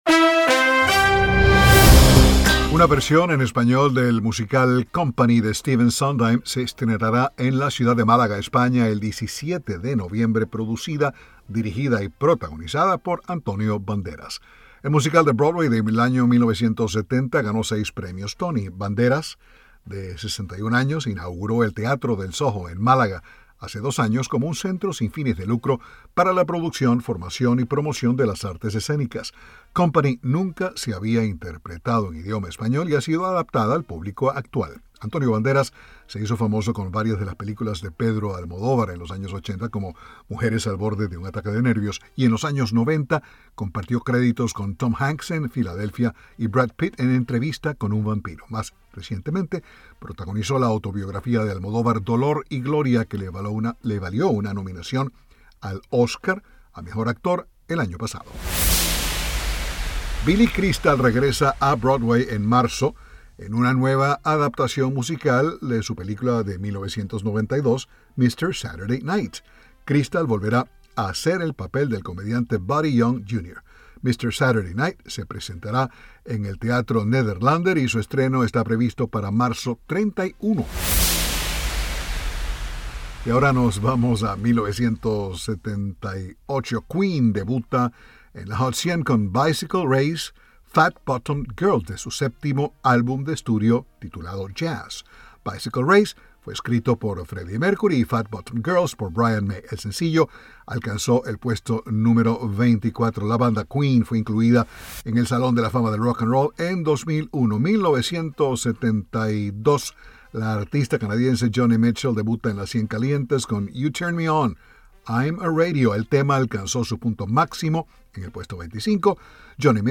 El musical ganador de seis premios Tony, del legendario creador estadounidense Stephen Sondheim, será puesto en escena por primera vez en español, la próxima semana en Málaga, España. Informa